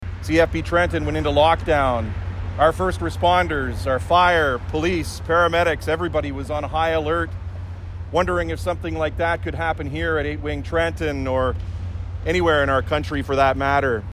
The almost 3,000 people killed by the 9/11 terrorist attacks on New York’s World Trade Centre were remembered Monday morning at a ceremony at the Belleville Fire and Emergency Services headquarters on Bettes Street.
One of the speakers was local MPP Todd Smith who said everyone was in shock that day, glued to their television sets wondering what might happen next.